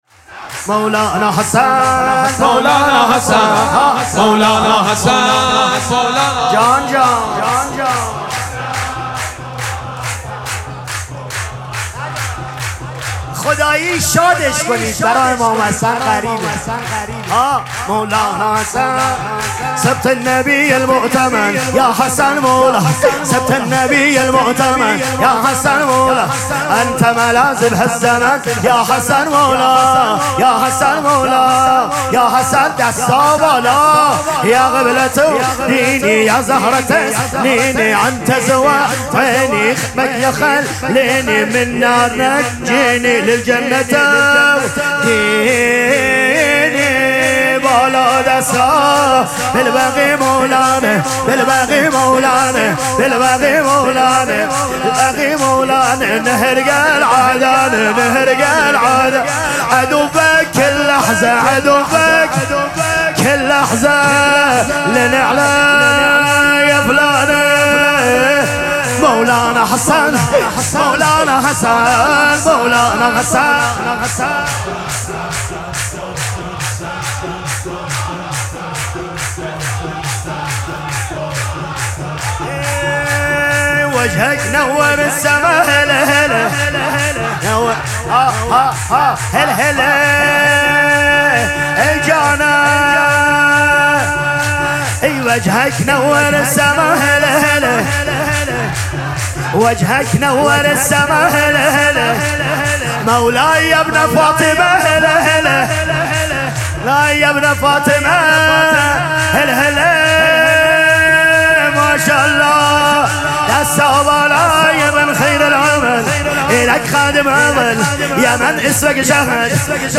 مراسم جشن شام ولادت امام حسن مجتبی(ع)
سرود
مداح